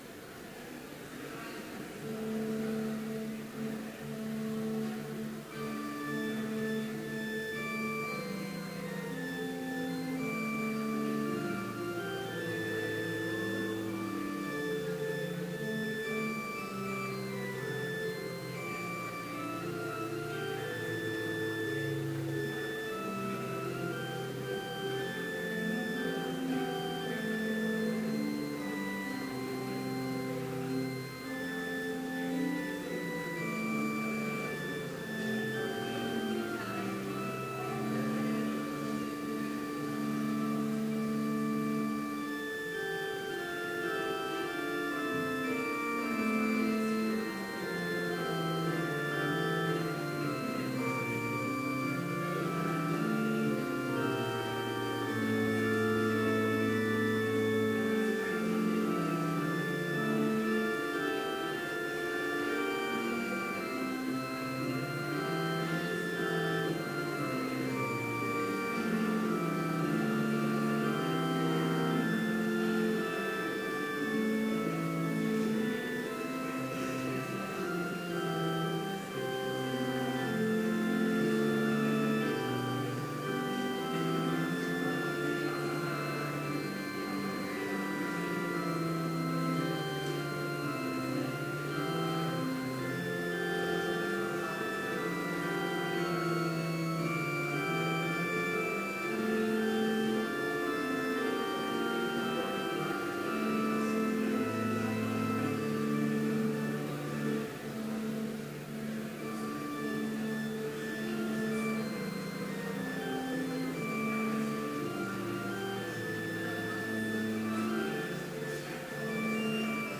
Complete service audio for Chapel - November 9, 2016